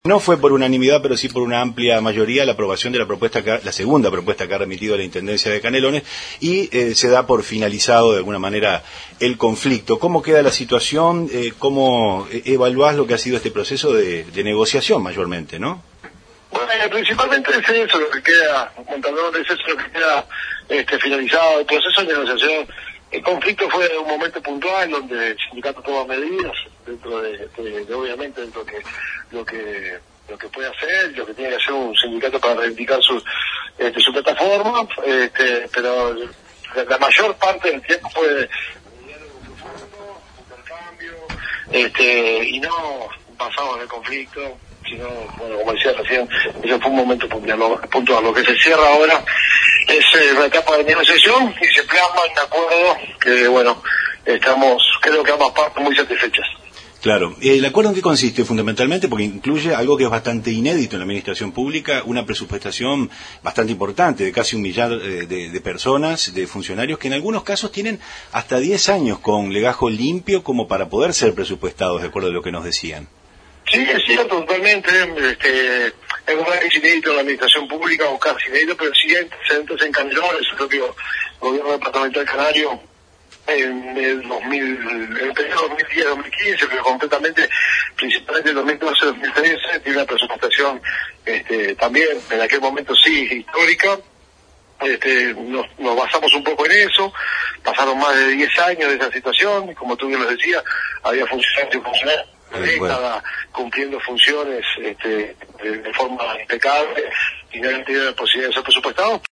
Pedro Irigoin, secretario general de la intendencia de Canelones, lo informó minutos después de culminada la asamblea a las radios públicas